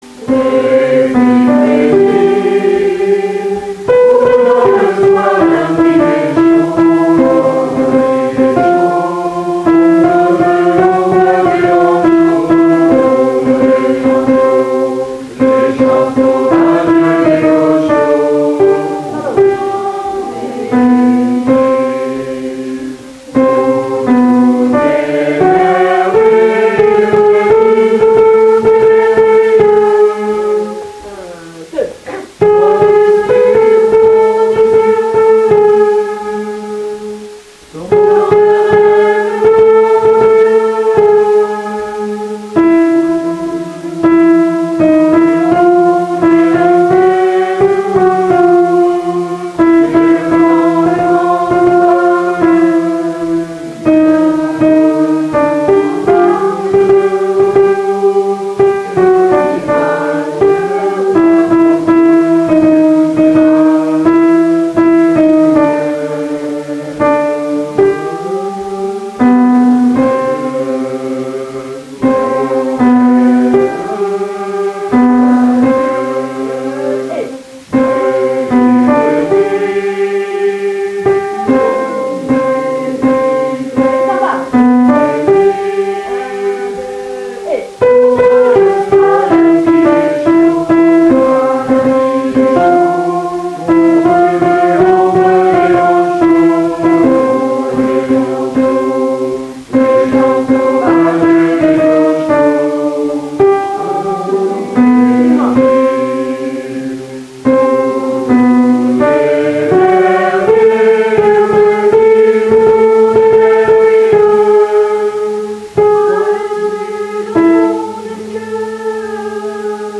Voici nos enregistrements du 1er juin. Il n’y a rien d’exceptionnel, mais pour répéter cela peut être utile.
Juste la voix 2 (celle des adultes)